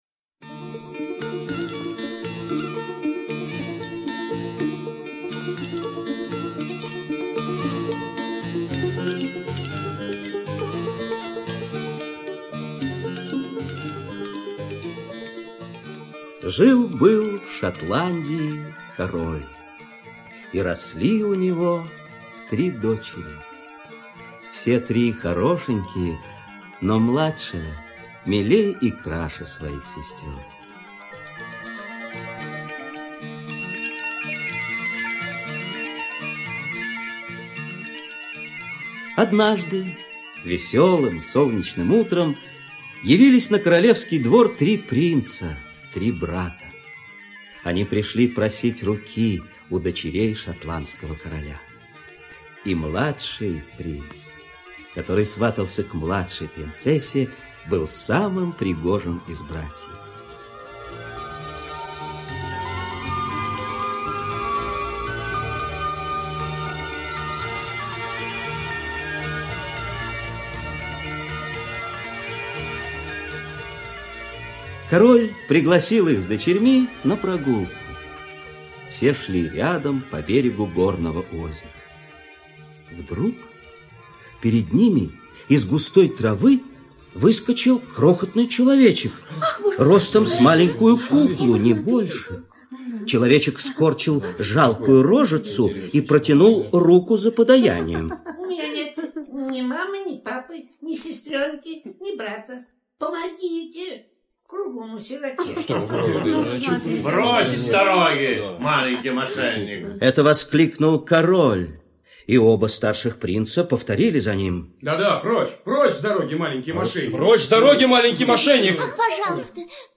Коротышка - шотландская аудиосказка - слушать онлайн